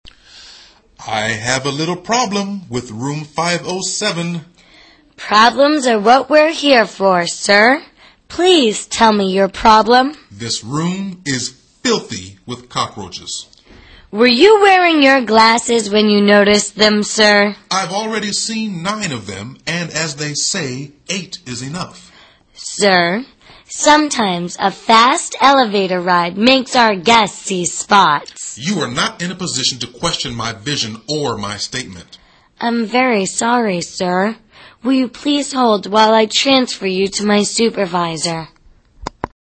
旅馆英语对话-Cockroach Problem(6) 听力文件下载—在线英语听力室